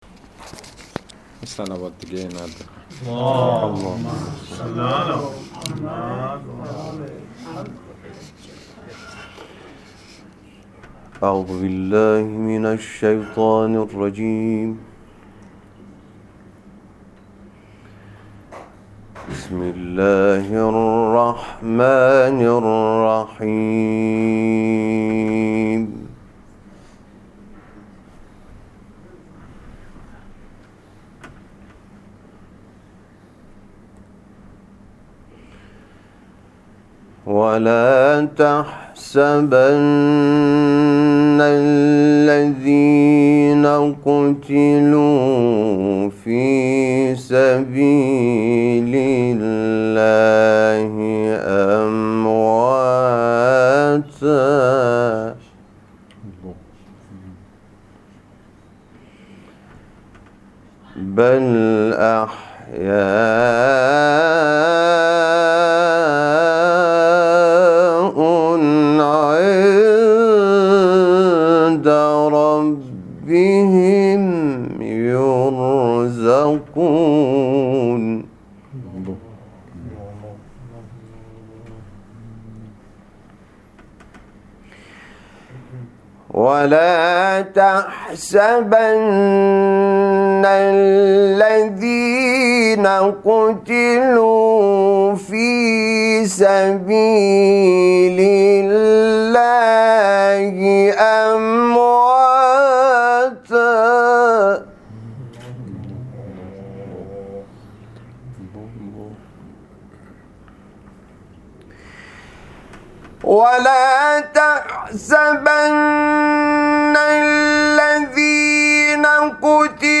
برچسب ها: حامد شاکرنژاد ، خانواده شهید ، دیدار با خانواده شهدا ، تلاوت قرآن ، خبرگزاری ایکنا ، خبرگزاری بین المللی قرآن